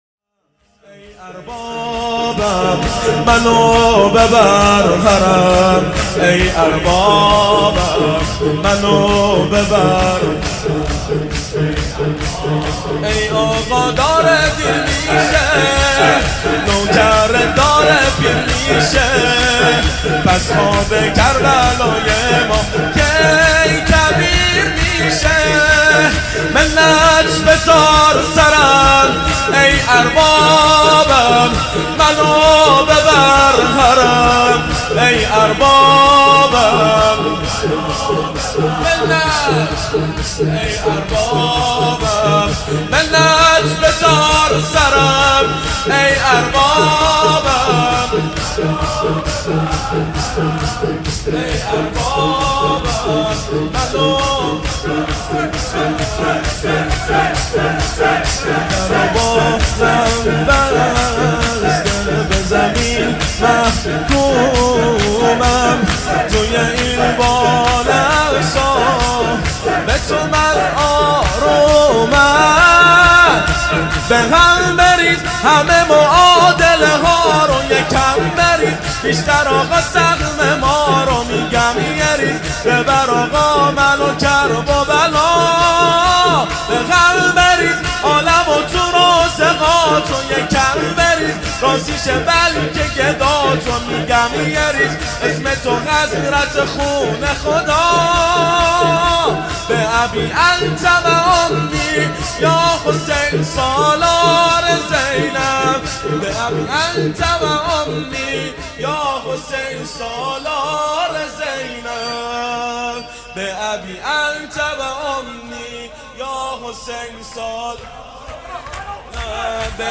شور | ای آقا داره دیر میشه، نوکرت داره پیر میشه
شب 7 محرم سال 1439 هجری قمری